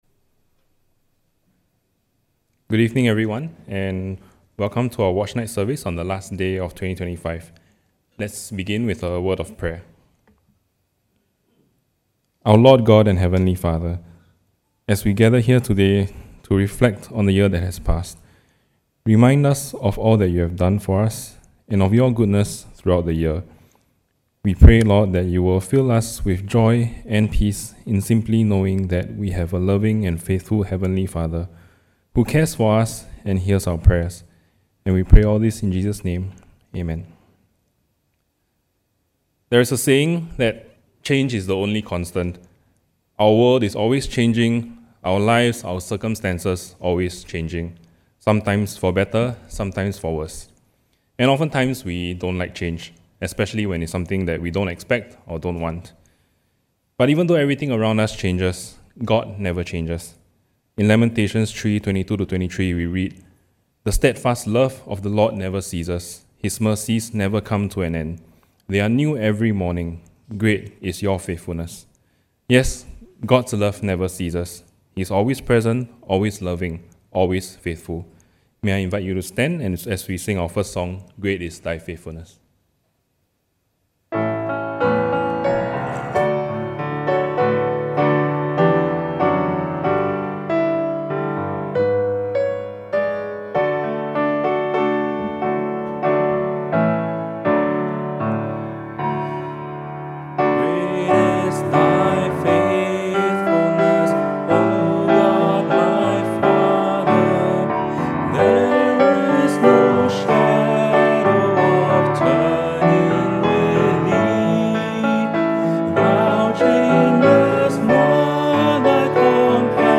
Watchnight Service 2025 - Bethesda (Katong) Church